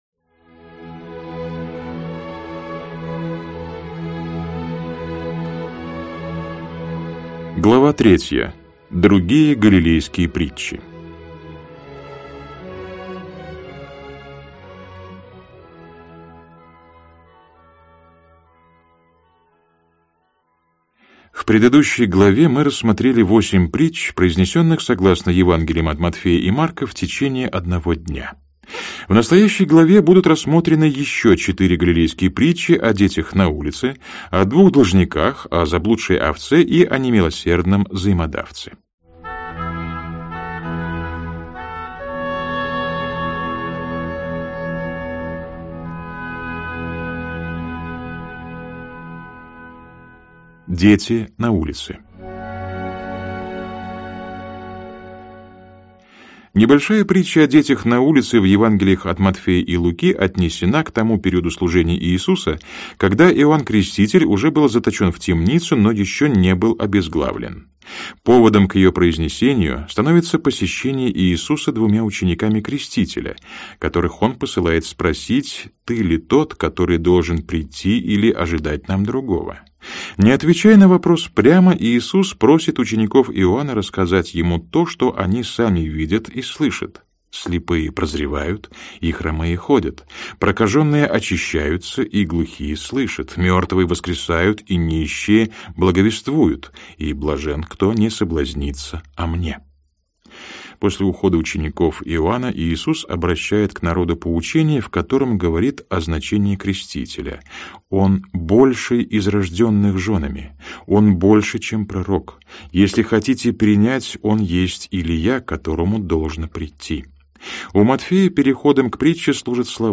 Аудиокнига Иисус Христос. Жизнь и учение. Книга IV. Притчи Иисуса. Глава 3. Другие галилейские притчи | Библиотека аудиокниг